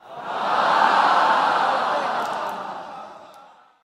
Звуки умиления в переполненном зале